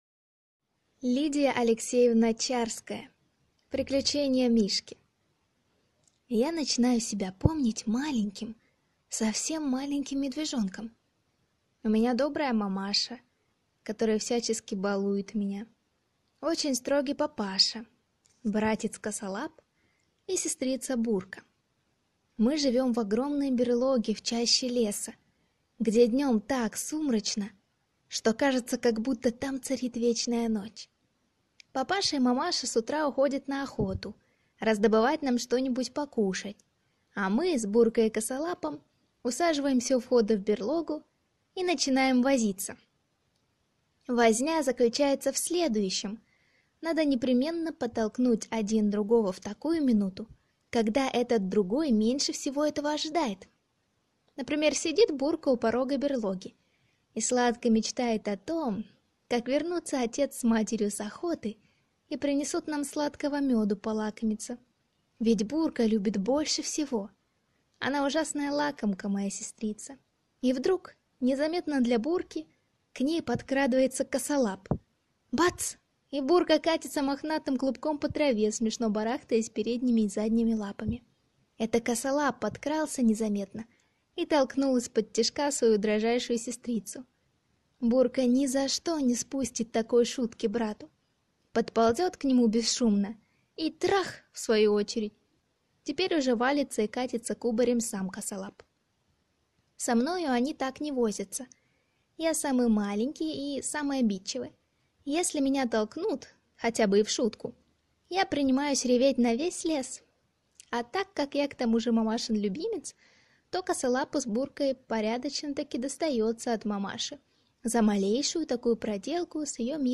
Аудиокнига Приключения Мишки | Библиотека аудиокниг